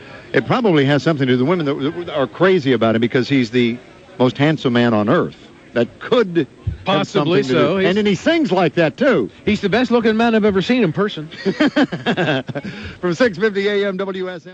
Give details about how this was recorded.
NASHVILLE'S WSM 650 AM REMOTE BROADCAST AT HERMITAGE WAL*MART / MARCH 25, 2004 Live remote broadcast with WSM 650 AM at the new Hermitage Wal*Mart Superstore in Nashville, TN